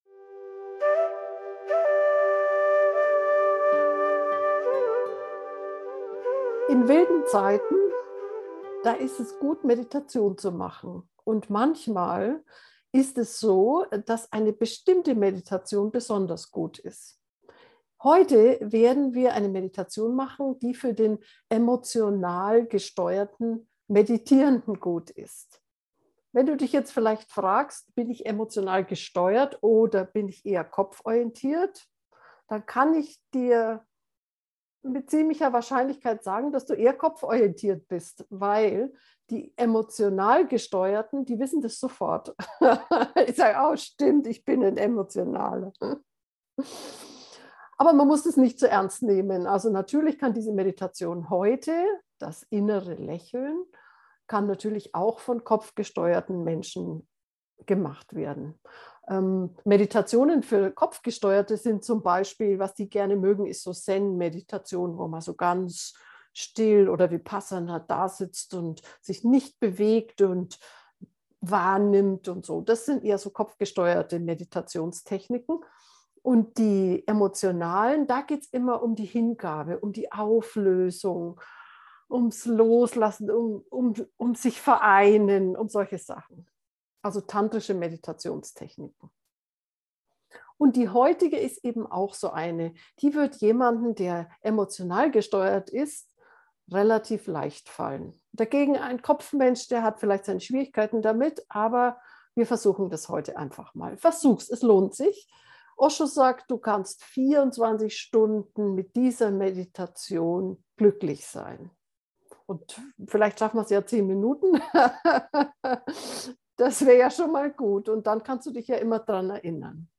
Herzmeditationen